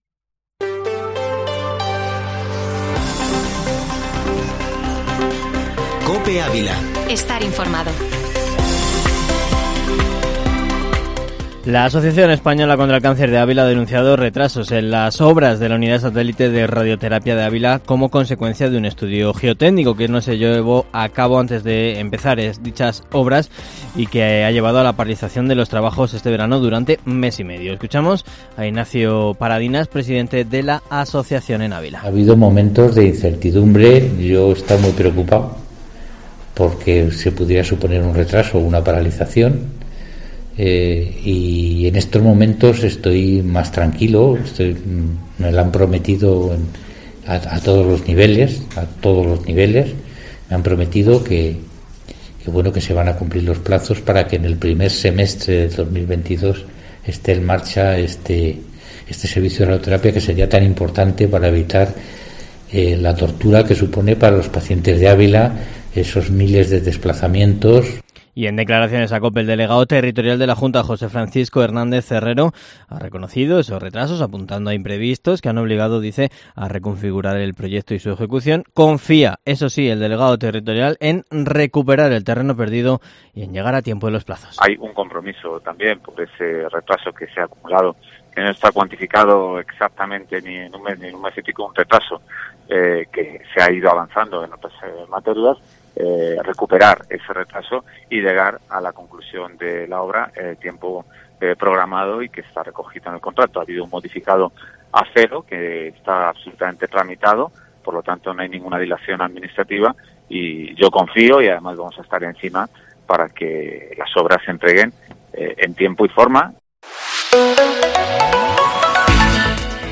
Informativo Matinal Herrera en COPE Ávila -29-sept